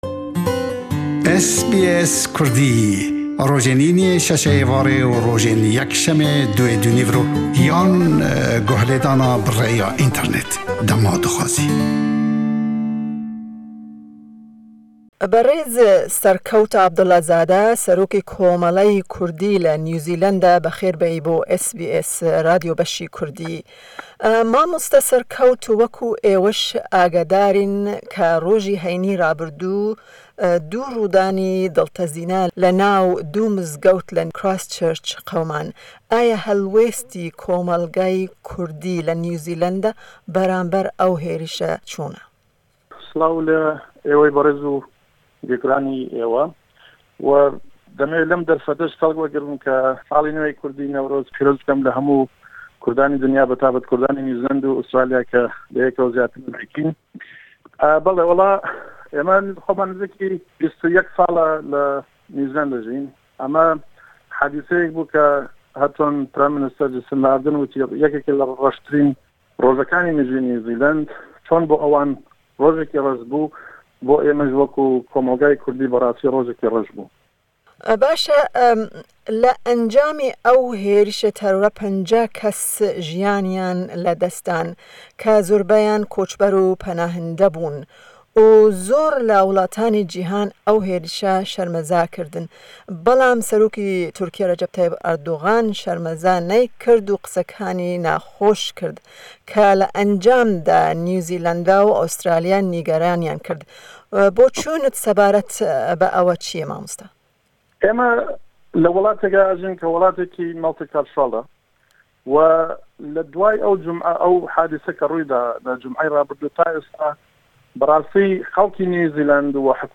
Hevpeyvîneke taybet ji New Zealand e